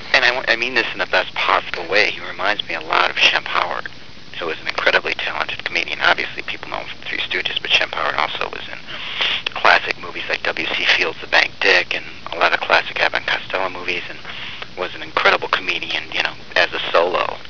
Q : Can you guess which Shemp Scott Spiegel is talking about when he says THIS ? When one of our list members had the opportunity to interview Scott Spiegel for an article, Scott got to talking about a certain someone's Shemp Howard-like abilities.